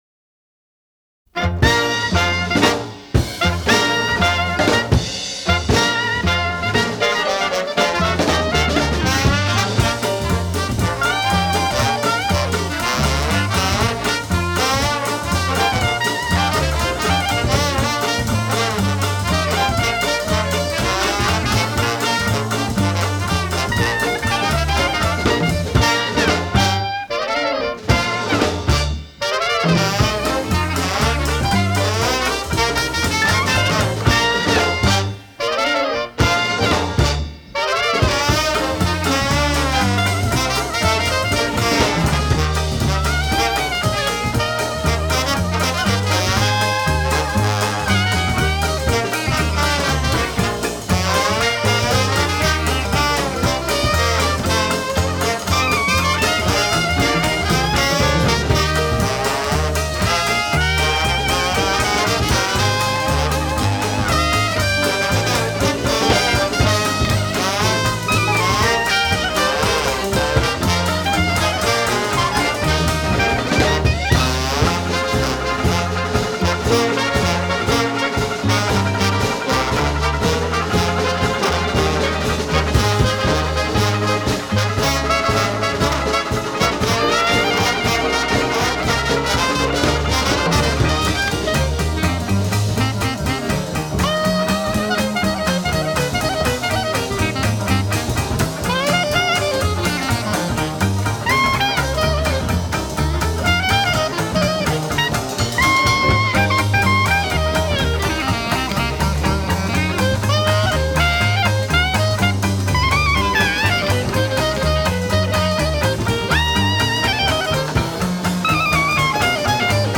Веселые диксиленды послушаем .
диксиленд